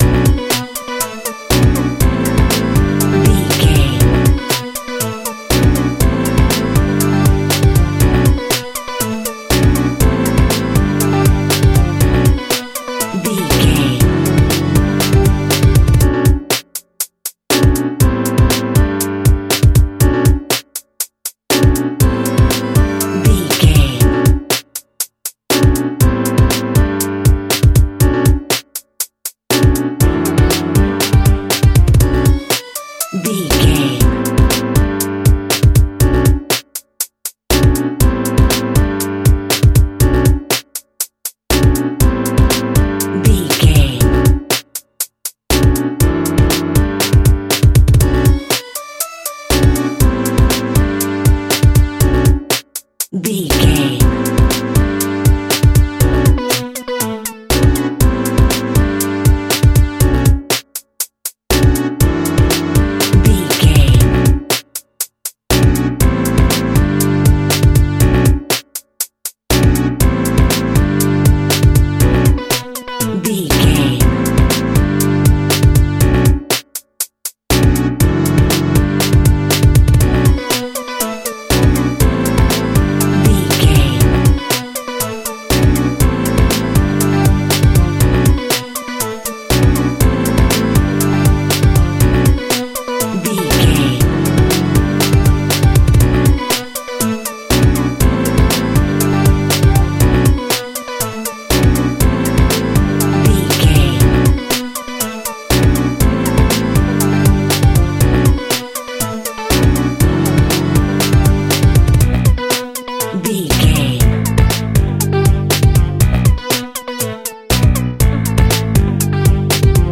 Aeolian/Minor
Funk
electronic
drum machine
synths